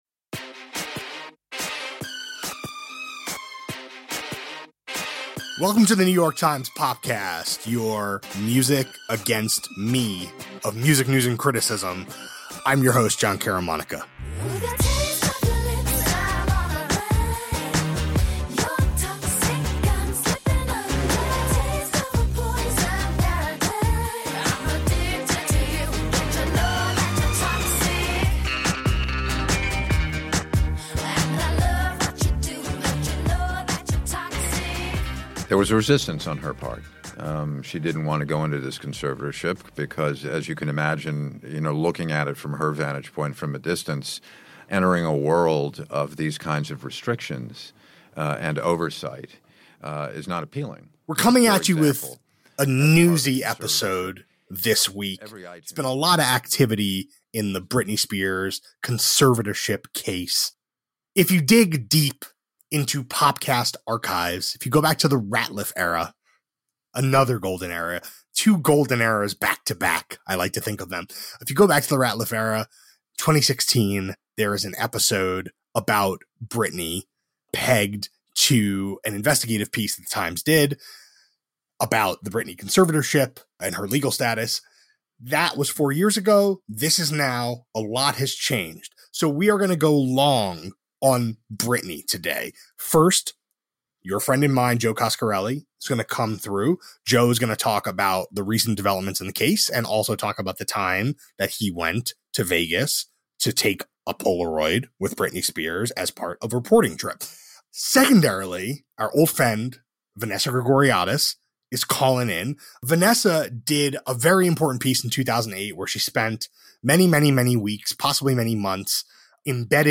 An update on the pop star's conservatorship, and a conversation about the mid-2000s tabloid era that was the kiln for her public unraveling.